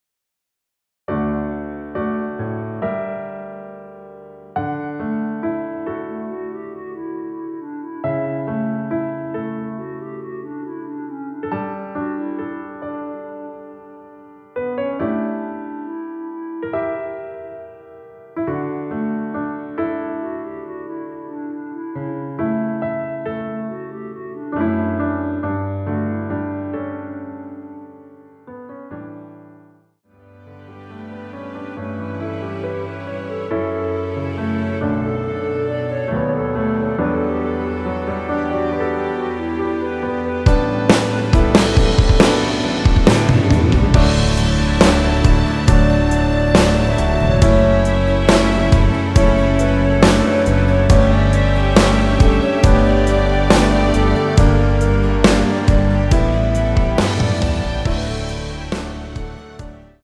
노래 들어가기 쉽게 전주 1마디 만들어 놓았습니다.(미리듣기 확인)
원키에서(-2)내린 (1절앞+후렴)으로 진행되는 멜로디 포함된 MR입니다.
앞부분30초, 뒷부분30초씩 편집해서 올려 드리고 있습니다.
중간에 음이 끈어지고 다시 나오는 이유는